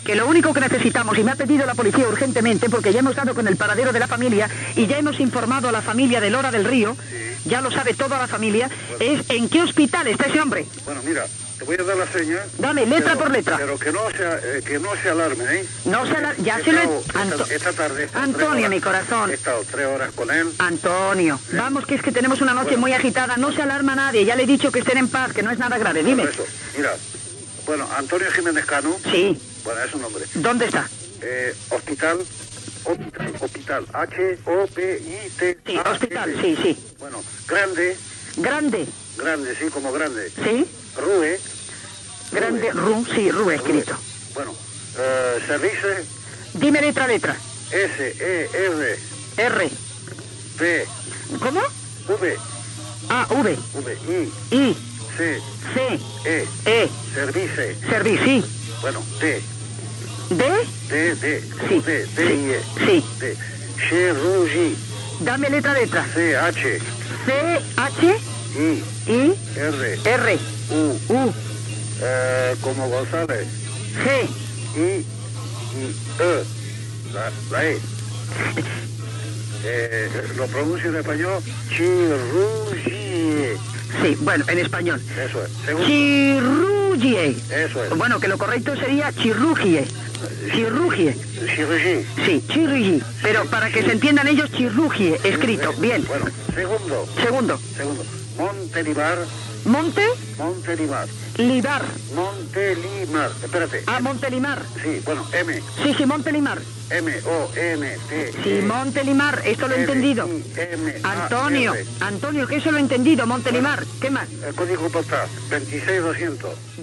Un oient dona unes senyes d'un hospital francès on es trobaven ingressats ciutadans espanyols després d'un accident.